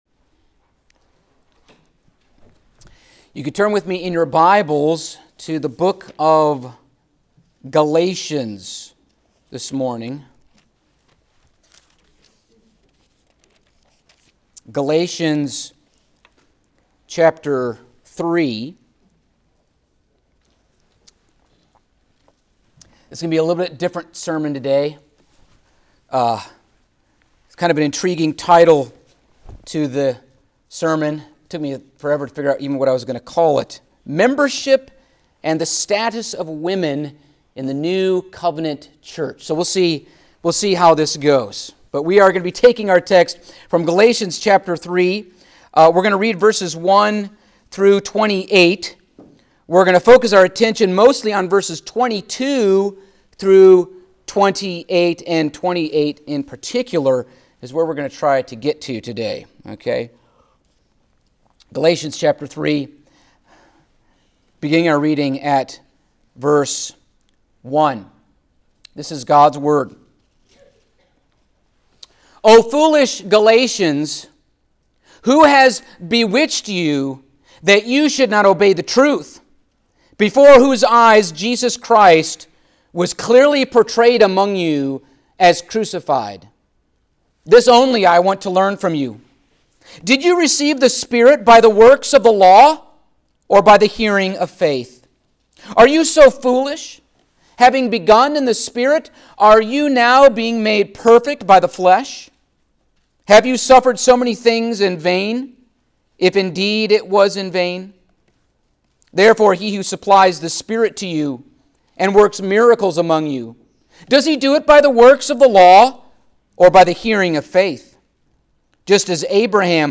Stand Alone Sermons
Service Type: Sunday Morning Topics: Baptism , Church Membership , Presbyterian Church Government , The Doctrine of the Church